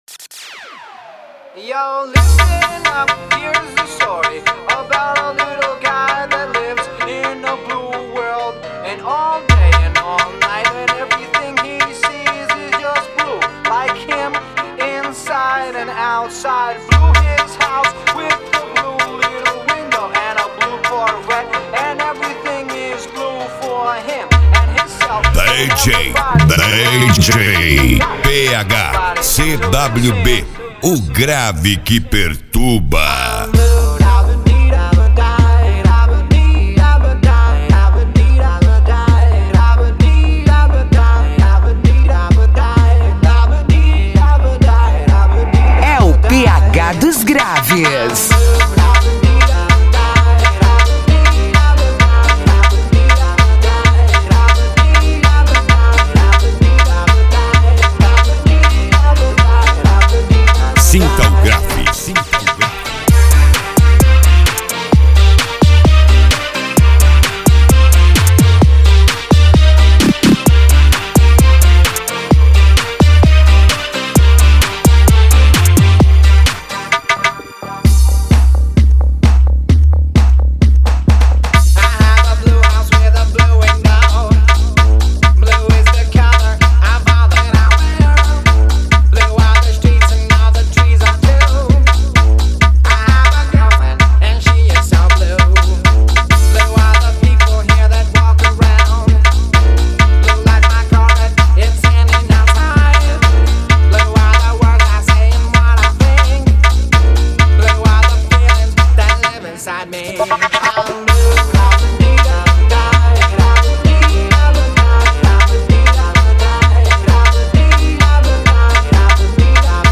Bass
Racha De Som